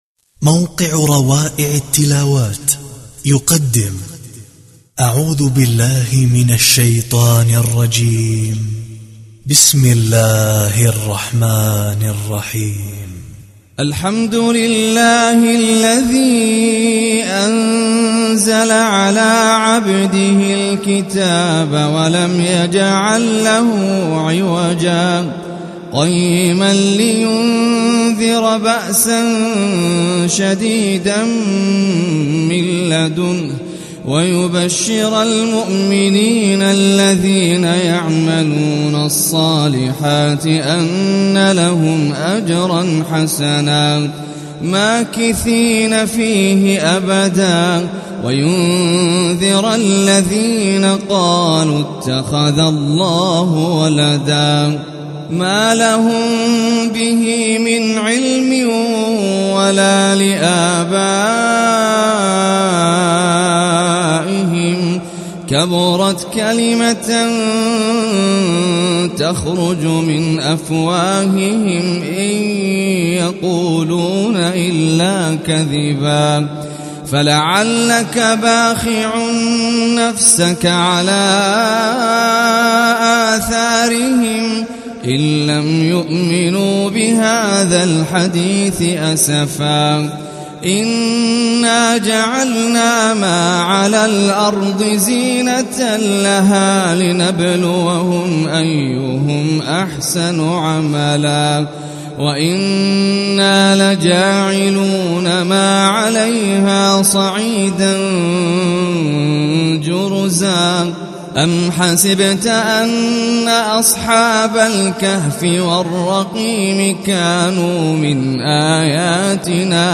سورة الكهف بصوت سلسلة التلاوات المشتركة